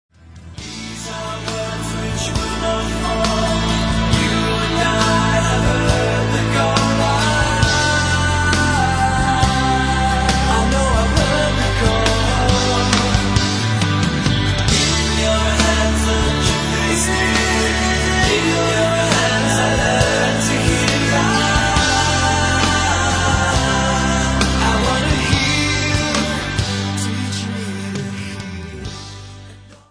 features four of the band members